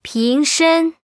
文件 文件历史 文件用途 全域文件用途 Erze_tk_01.ogg （Ogg Vorbis声音文件，长度0.0秒，0 bps，文件大小：15 KB） 源地址:游戏语音 文件历史 点击某个日期/时间查看对应时刻的文件。